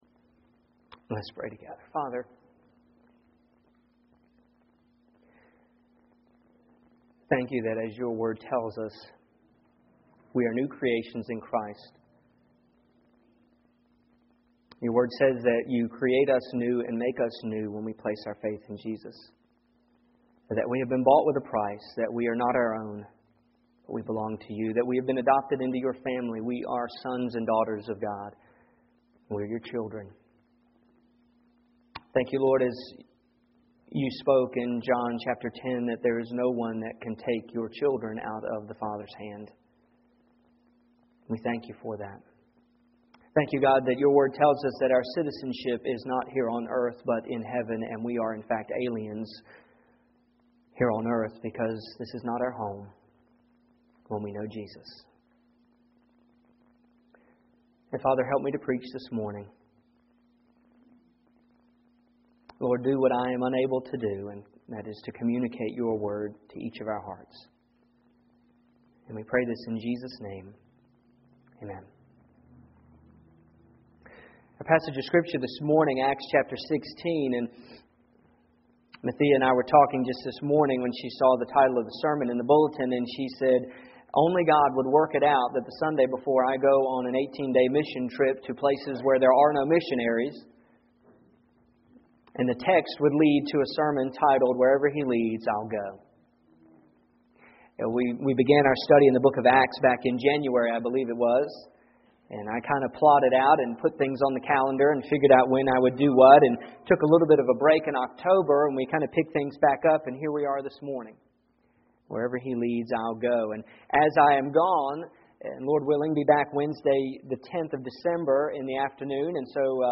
Here is my sermon from November9th.
I preached this right after she shared her testimony.